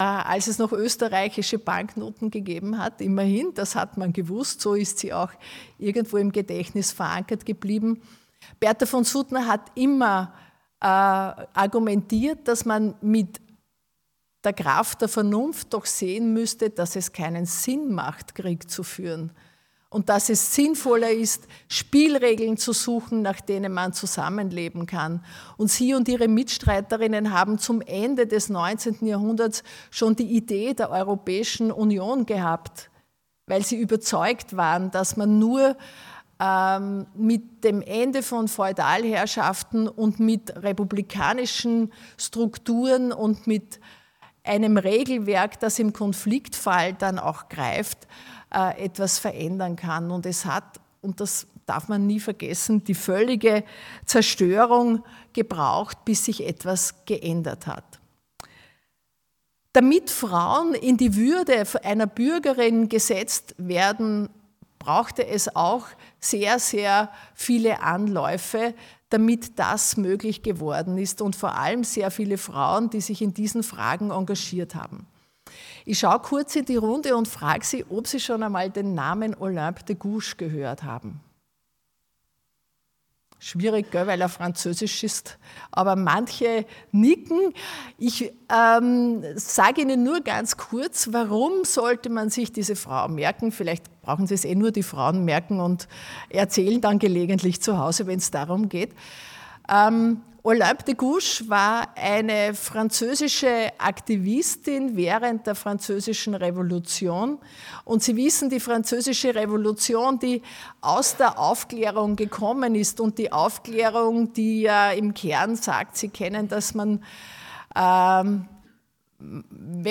Der Festvortrag hier zum Nachhören: